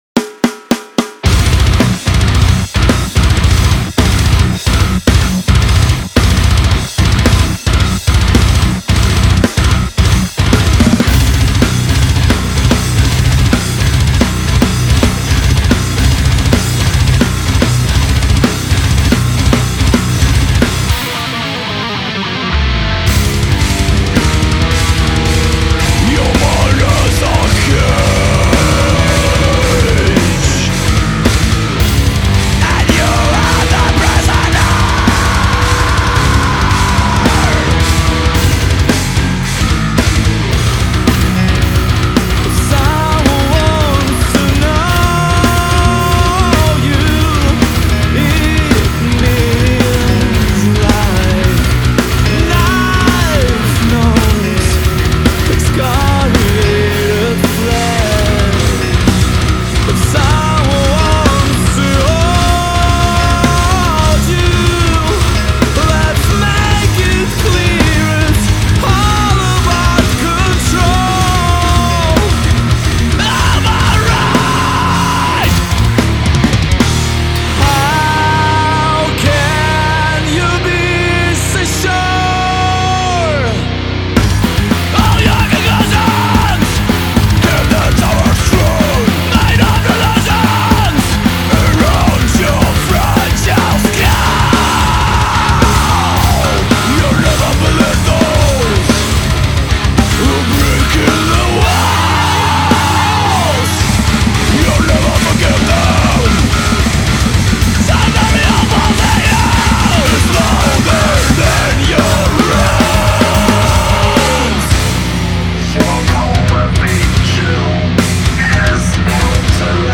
Math Metal